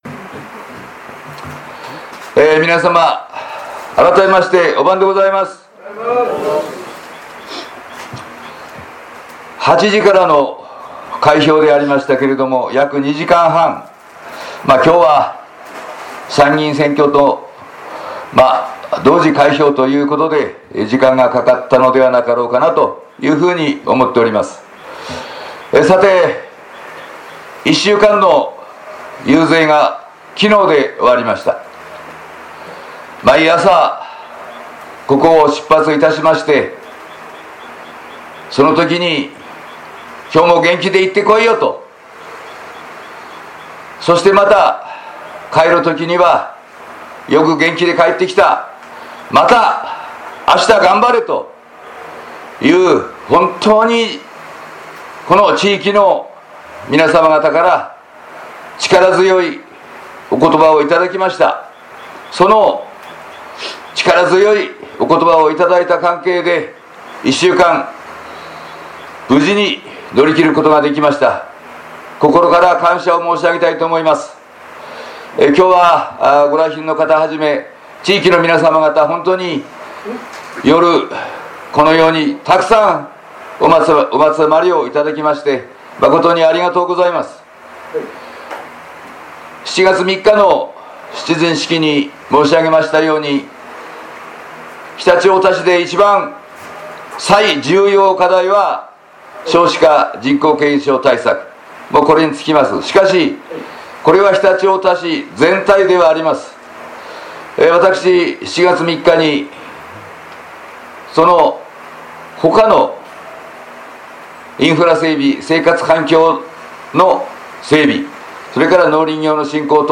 菊池勝美 当選の挨拶